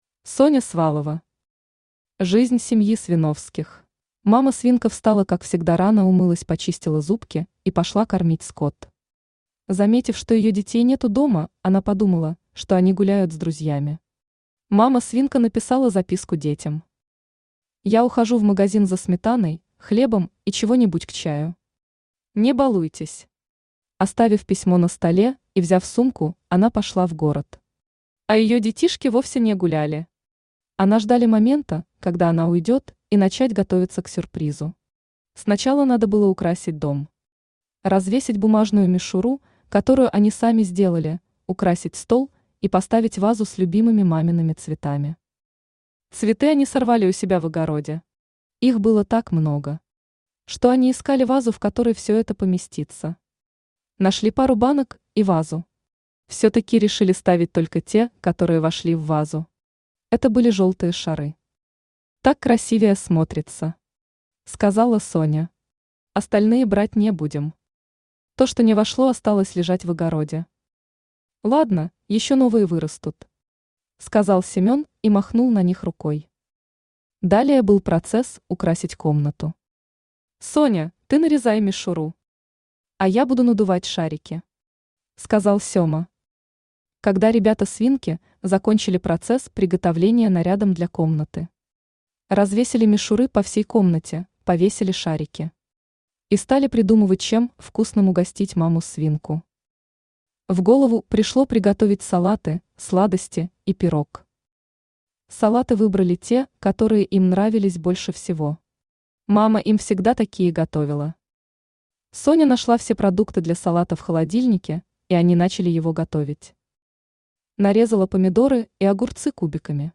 Читает: Авточтец ЛитРес